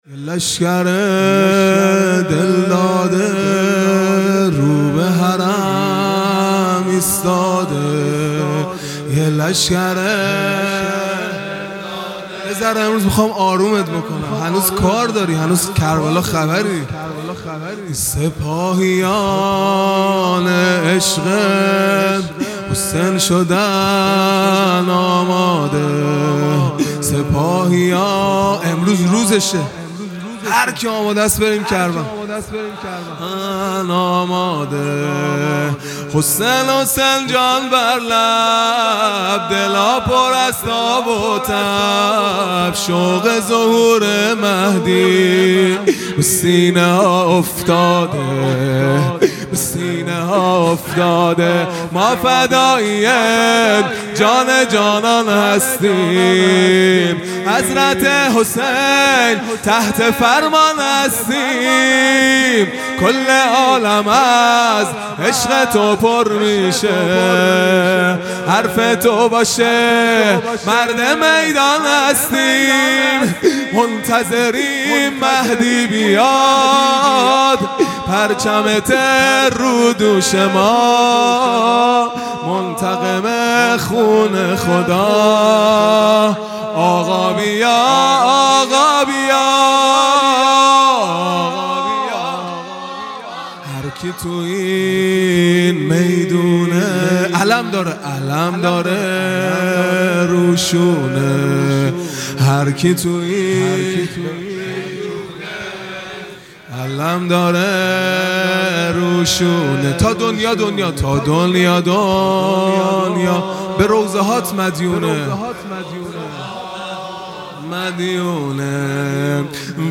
خیمه گاه - هیئت بچه های فاطمه (س) - رجز | یه لشکر دلداده رو به حرم ایستاده | دوشنبه ۱۷ مرداد ماه ۱۴۰۱